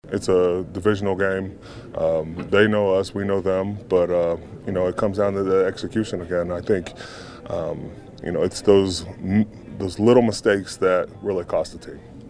Heyward says it’s another AFC North game and the Steelers will have to pay attention to details to win on Sunday.